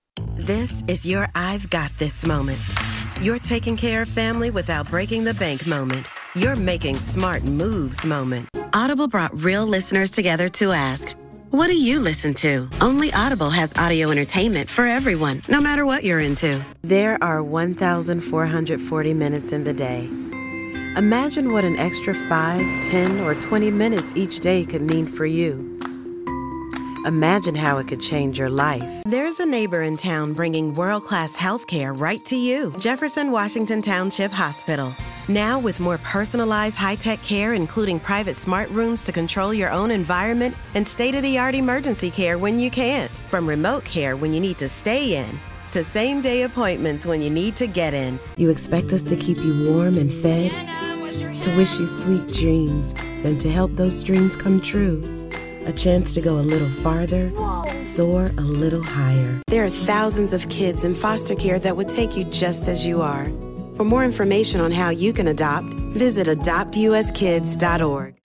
Has Own Studio
COMMERCIAL 💸
conversational
warm/friendly